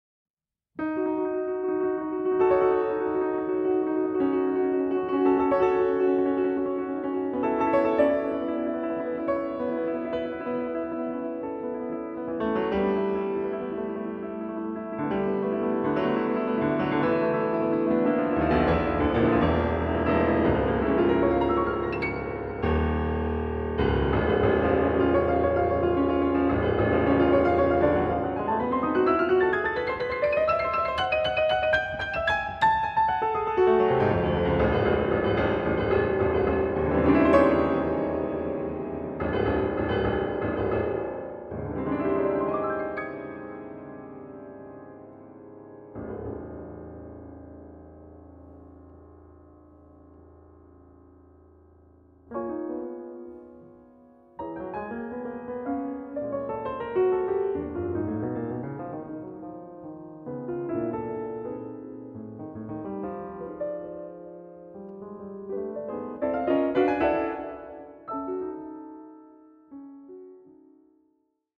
• Genres: Solo Piano, Classical
Recorded at LeFrak Concert Hall, Queens College, CUNY, 2011